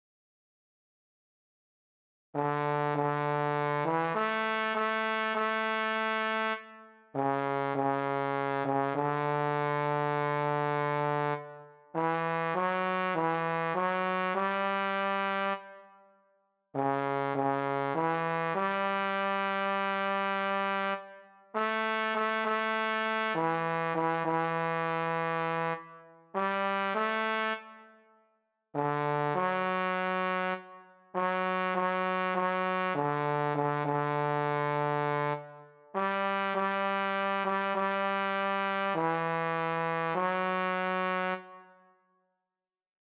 Key written in: D Major
How many parts: 4
Type: Barbershop
Each recording below is single part only.